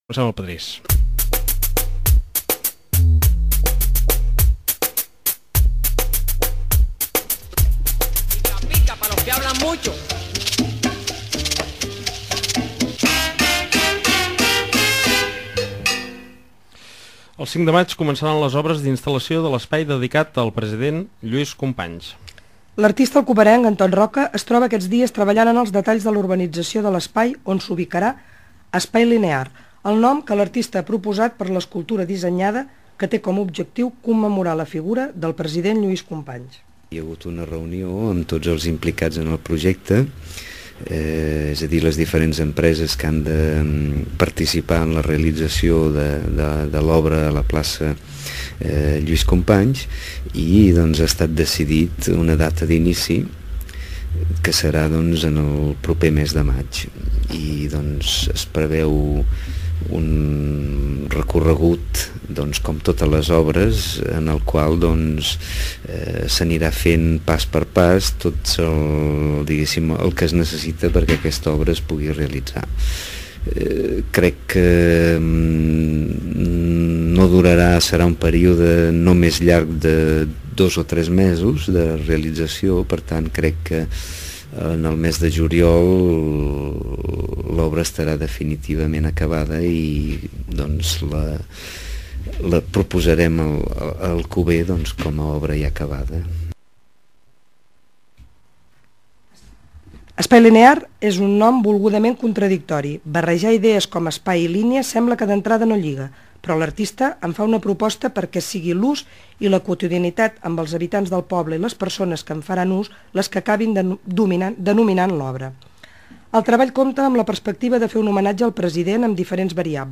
entrevista.wma